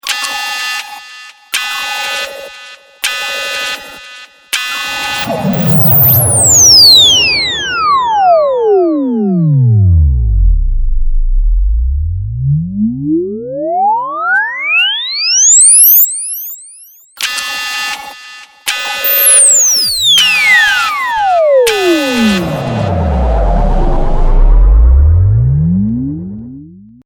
This is an MP3 Ringtone